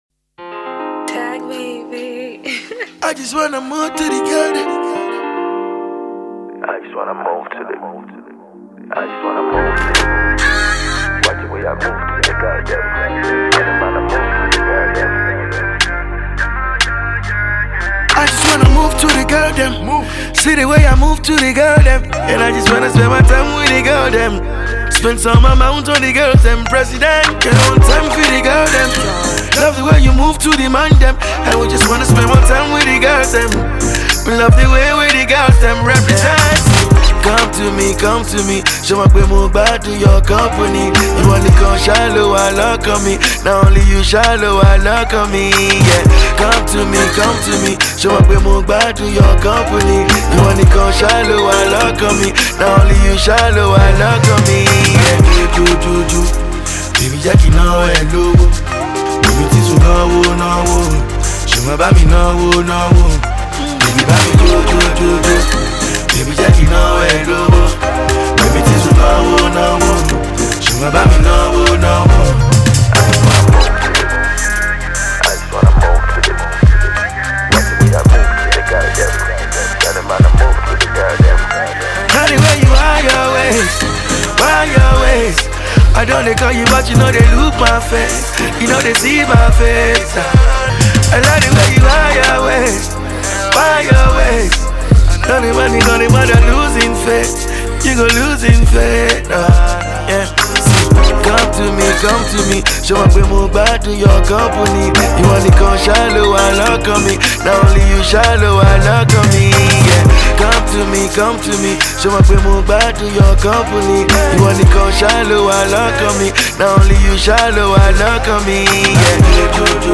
calm, soothing Afro-Pop sound